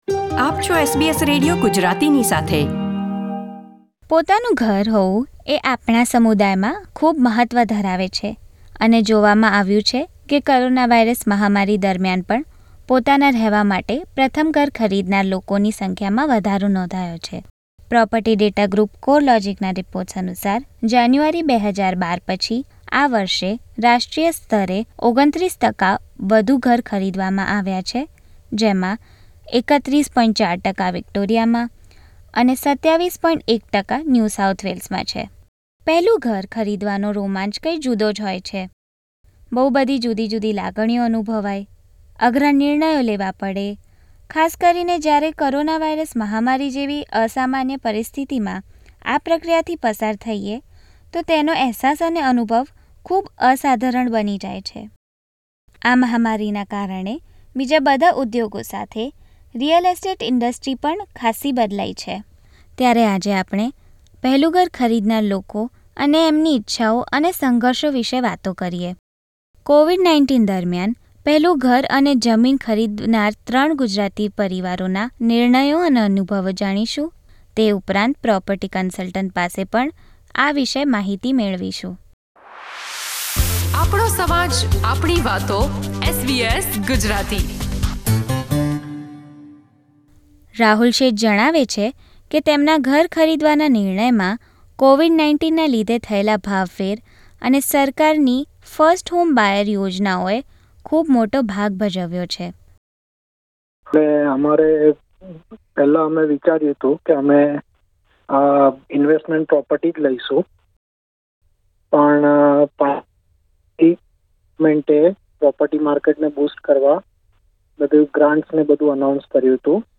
First home buyers share their experiences of buying and selling home amid coronavirus pandemic.